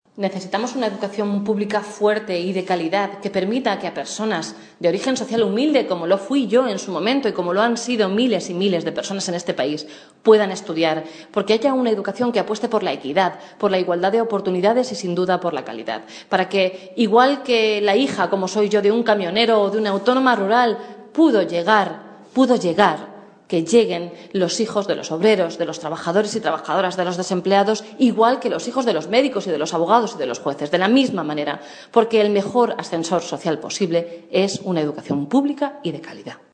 En un acto en Almadenejos
Cortes de audio de la rueda de prensa